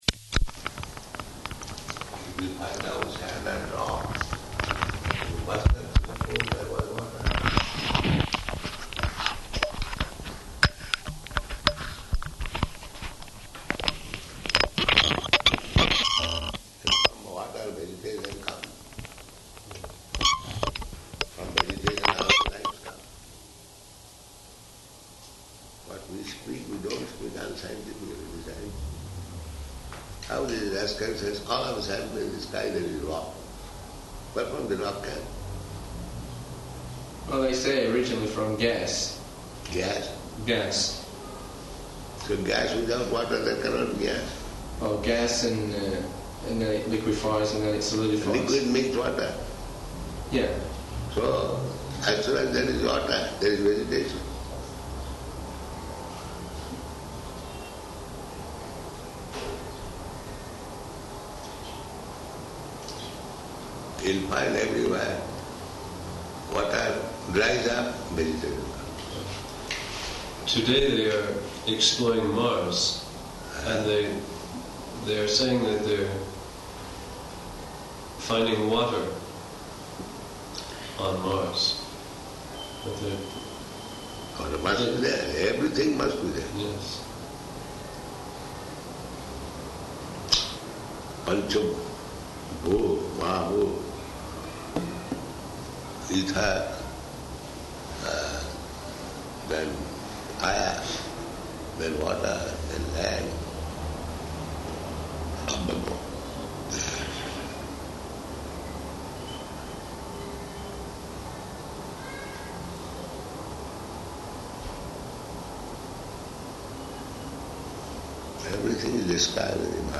Room Conversation
Room Conversation --:-- --:-- Type: Conversation Dated: August 9th 1976 Location: Tehran Audio file: 760809R1.TEH.mp3 Prabhupāda: If you find out sand and rocks, you must also [indistinct] there was water.